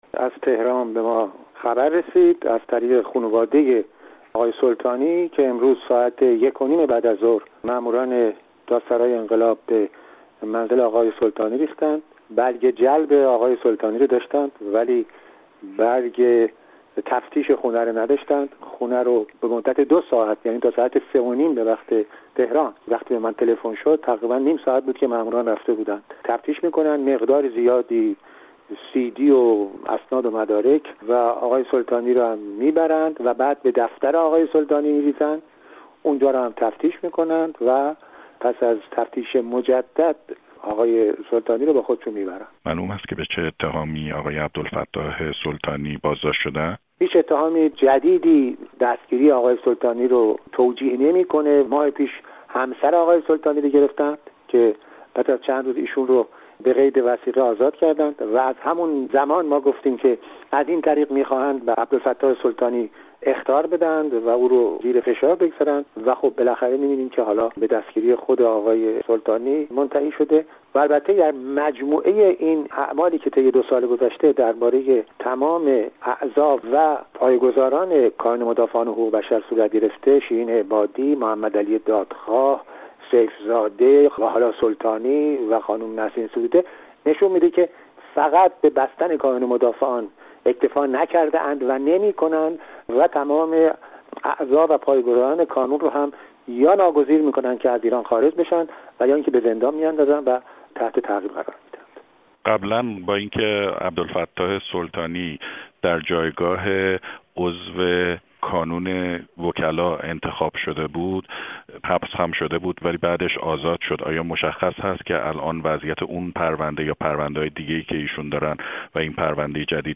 آقای لاهیجی در گفت‌وگو با رادیو فردا جزئیاتی بیشتر را در مورد بازداشت آقای سلطانی مطرح می‌کند.
گفت‌وگو با عبدالکریم لاهیجی در مورد بازداشت دوباره عبدالفتاح سلطانی